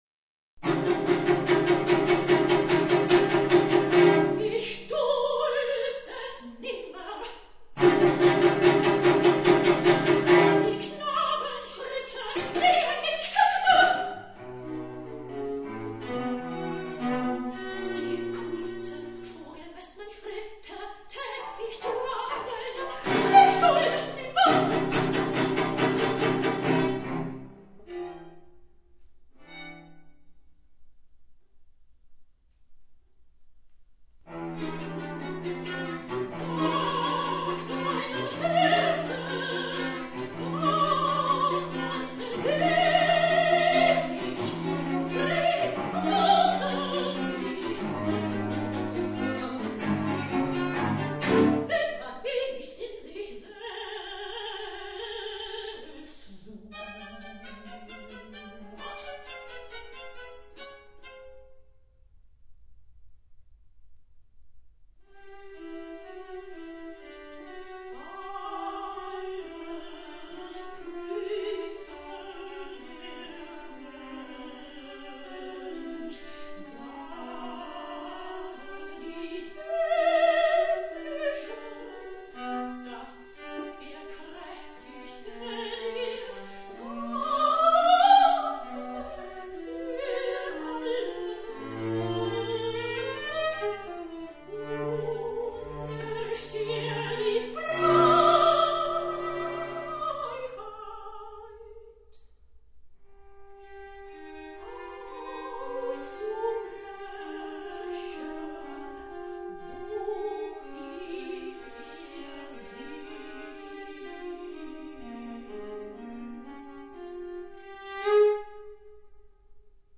für Mezzosopran, Violine, Viola und Violoncello